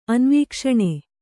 ♪ anvīkṣaṇe